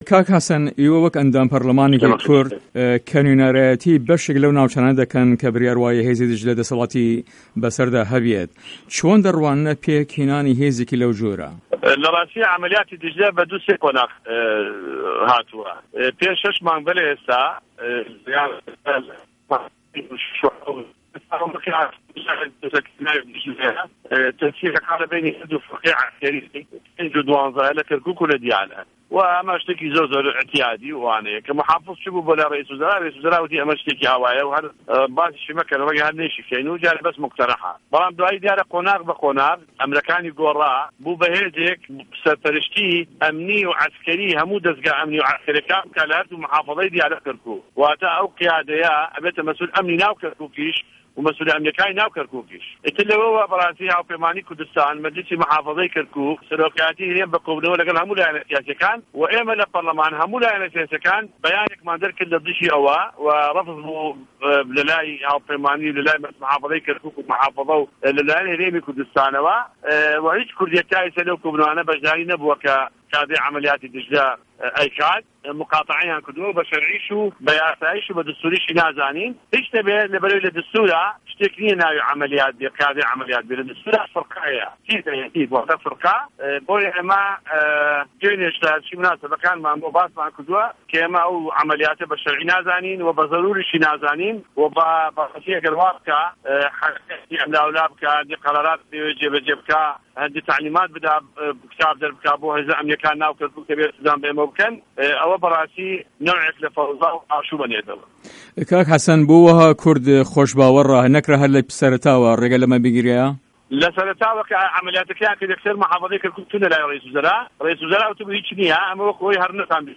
وتو وێژ له‌گه‌ڵ حه‌سه‌ن جیهاد